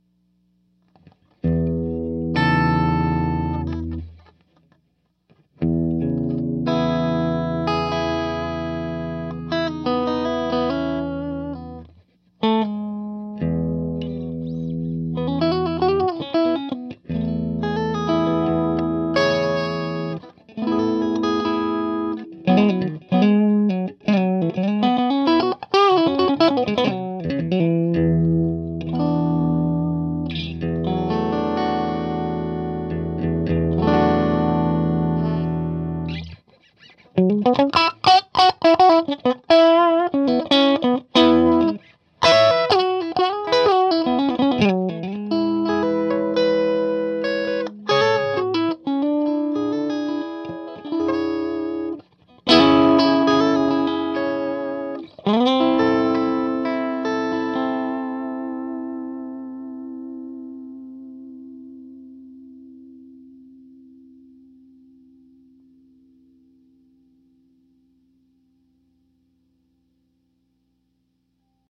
The cab was mic'ed with a single SM57 aimed at the upper right hand speaker.
JV 62 RI Strat - Pickups from an 83 strat.
All of the clips were done at very low volume except for the les paul clips that were done at very high volume.
Way too bright and clangy for me but it could be my crappy notebook speakers.
Strat_Clean221nnn3.mp3